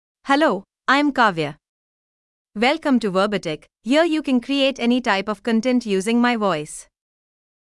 FemaleEnglish (India)
Kavya is a female AI voice for English (India).
Voice sample
Kavya delivers clear pronunciation with authentic India English intonation, making your content sound professionally produced.